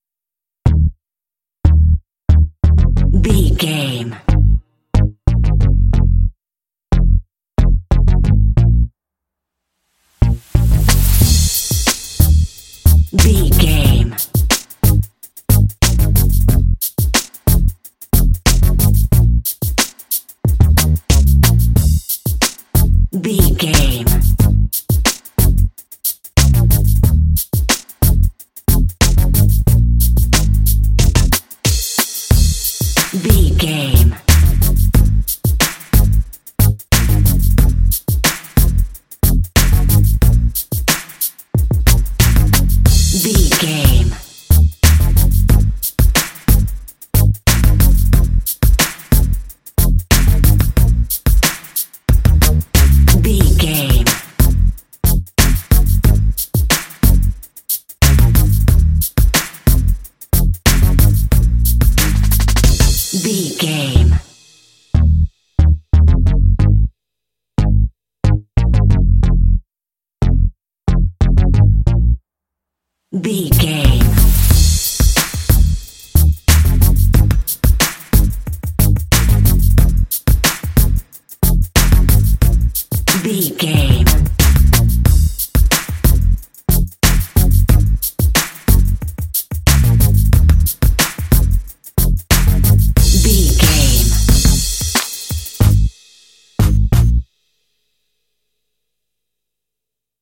Aeolian/Minor
B♭
cool
futuristic
synthesiser
bass guitar
drums
synth-pop